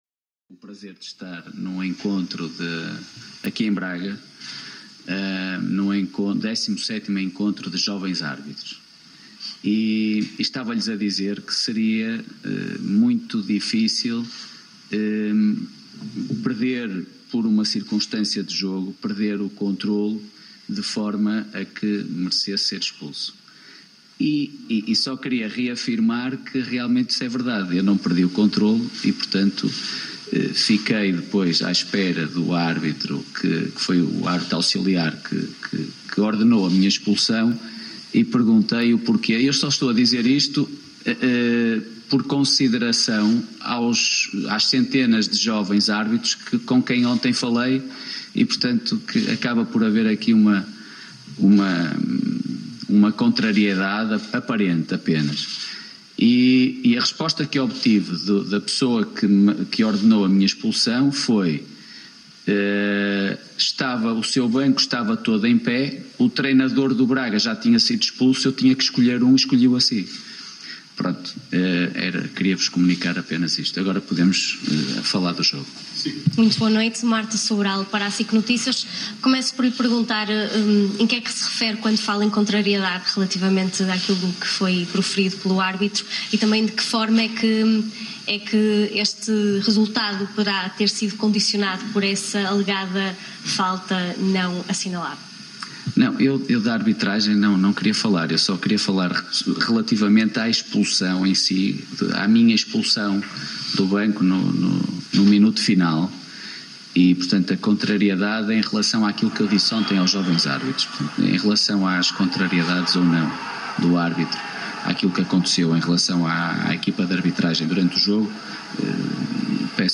conferência-1.mp3